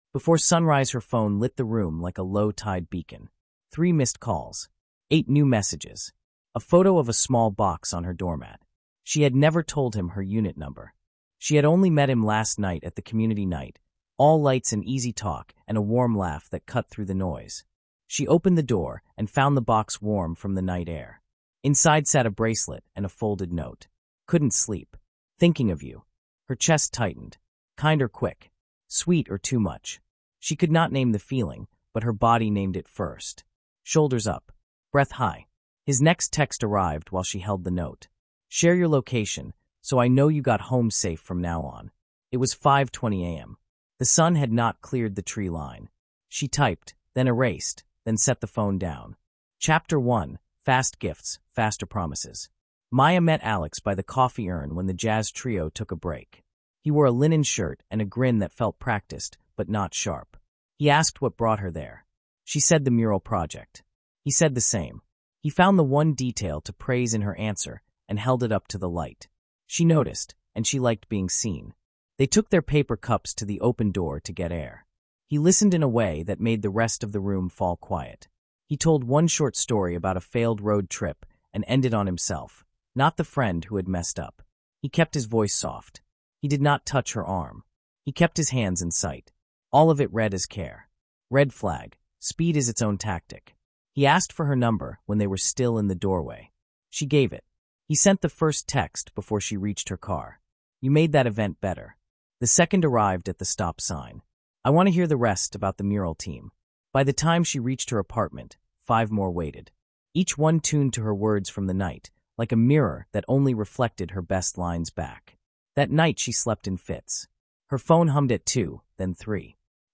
In this gripping episode of our limited series, we delve into the world of emotional coercion and covert psychological abuse, where love can turn dark. Listeners will hear compelling narratives from survivors who draw clean lines and maintain important boundaries against manipulative tactics often hidden in relationships. This audio experience features short, clear beats and focuses on real behaviors, avoiding sensationalism to provide an insightful look into the impact of manipulation in personal dynamics.